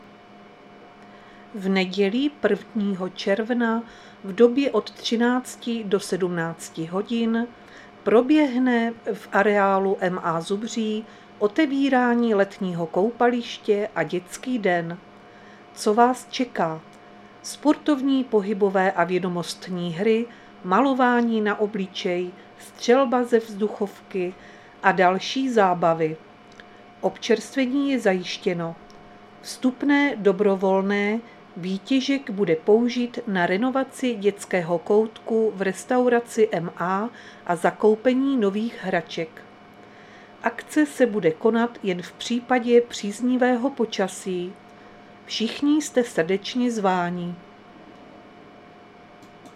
Záznam hlášení místního rozhlasu 28.5.2025
Zařazení: Rozhlas